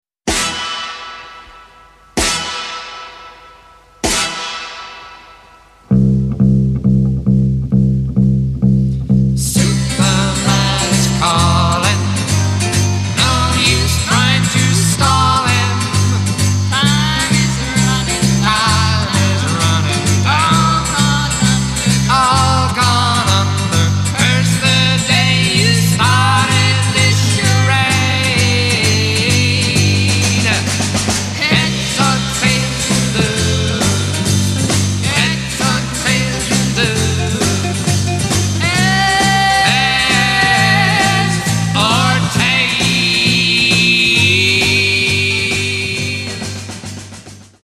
Crime Jazz at its best!